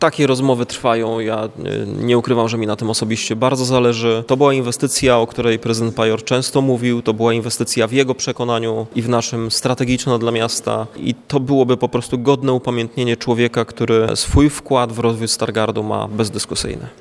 Czy Zintegrowane Centrum Przesiadkowe będzie nosiło imię Sławomira Pajora? Pomysł ten podczas dzisiejszej konferencji prasowej przedstawił prezydent Stargardu – Rafał Zając.
zając centrum przesiadkowe imienia pajora.mp3